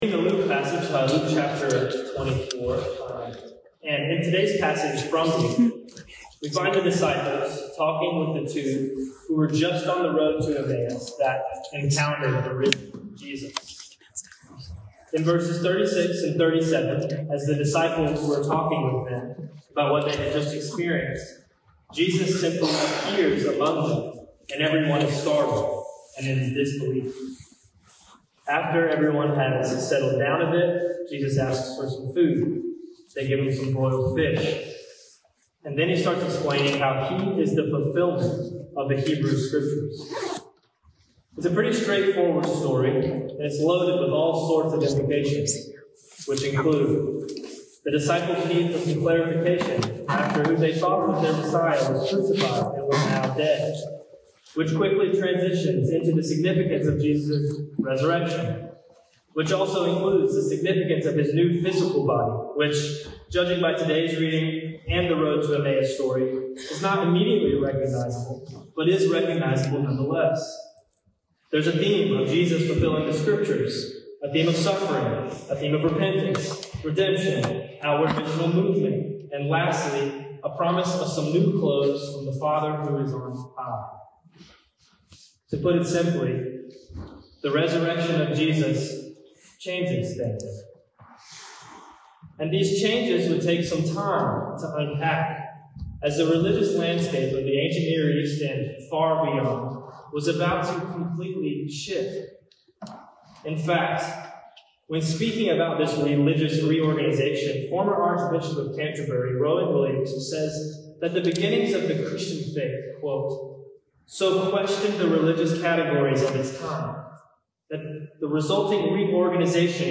We apologize for the audio quality due to technical difficulties with the recording.